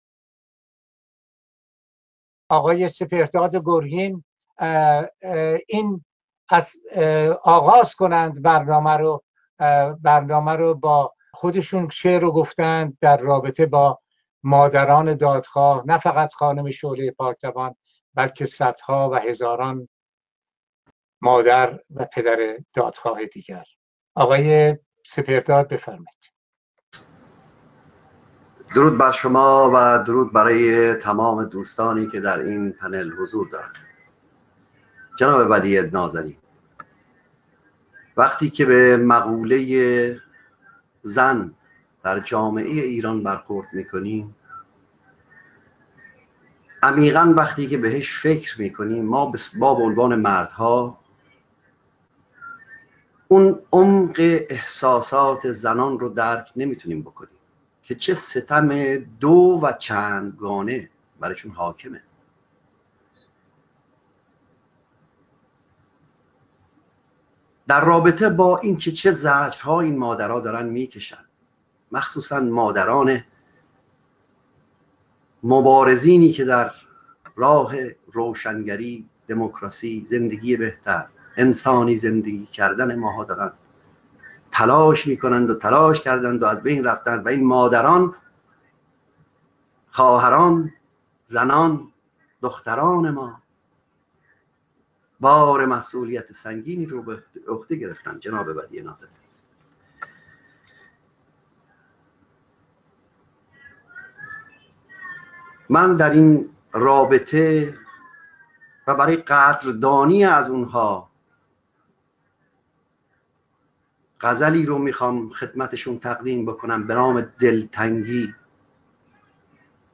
به گزارش گذار (سامانه دموکراسی و داد)، وبینار سراسری گذار از استبداد اسلامی به دموکراسی، با موضوع راهکارهای میدانی برای گذار خشونت پرهیز از استبداد اسلامی به دموکراسی، در جهت حمایت و شرکت فعال ایرانیان خارج از کشور از جنبش اعتراضی برای تغییرات بنیادی در حاکمیت سیاسی، با حضور جمعی از کنشگران مدنی، صاحب‌نظران و فعالان سیاسی، شامگاه یک‌شنبه ۲۵ نوامبر ۲۰۱۸ (۴ آذرماه ۱۳۹۷) برگزار شد.